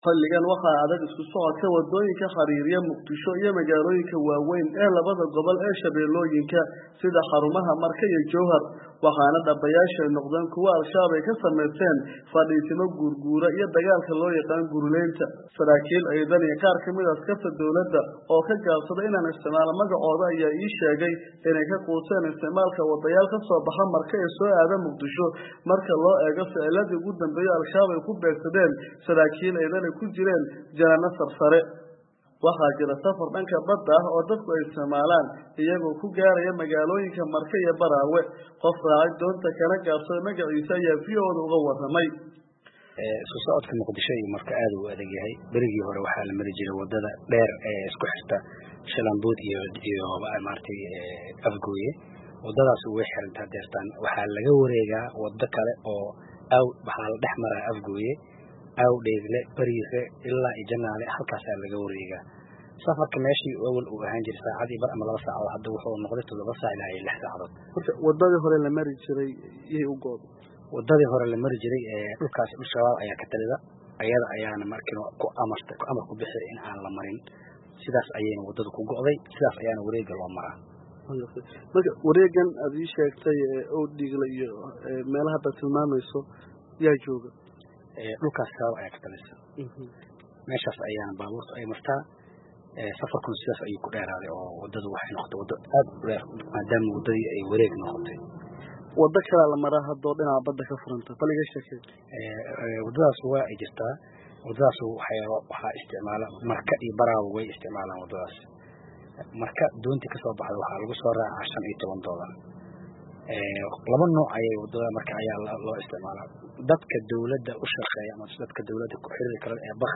Warbixin ku saabsan amniga Muqdisho iyo hareeraheeda